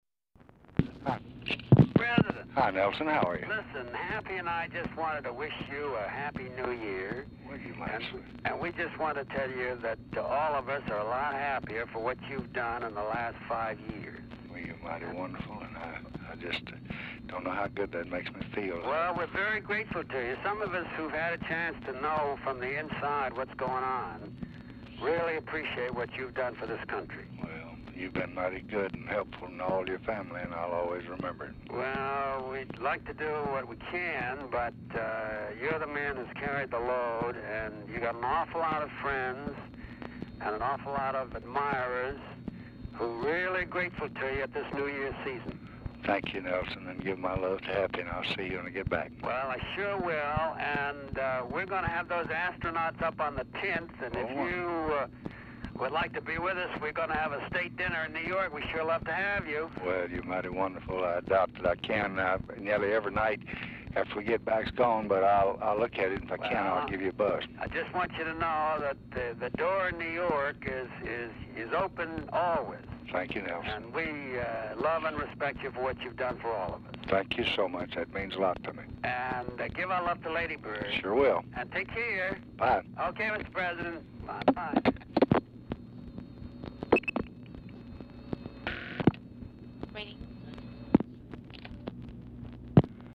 Telephone conversation # 13903, sound recording, LBJ and NELSON ROCKEFELLER, 1/1/1969, 2:31PM | Discover LBJ
Format Dictation belt
Location Of Speaker 1 LBJ Ranch, near Stonewall, Texas